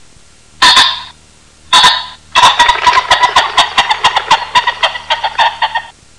Звуки фазана
На этой странице собраны разнообразные звуки фазанов – от характерного квохтания до резких тревожных криков.
Звук манка фазана для успешной охоты